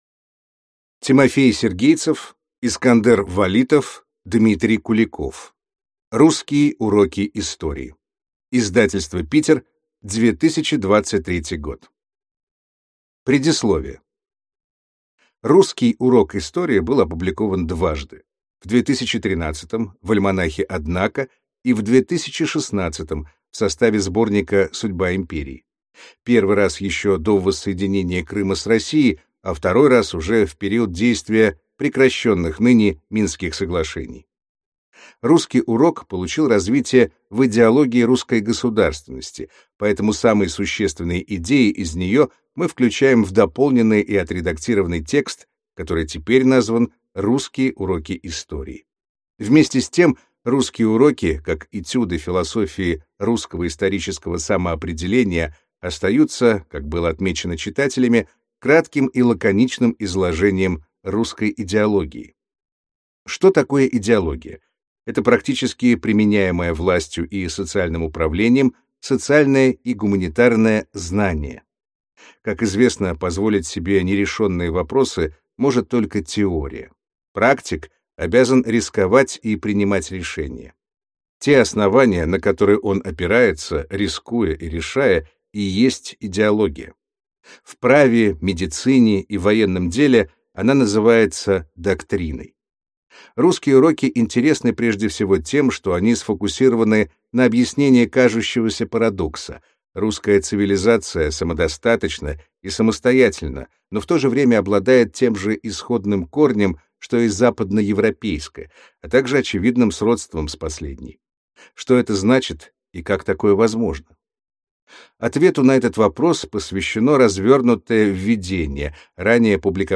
Аудиокнига Русские уроки истории | Библиотека аудиокниг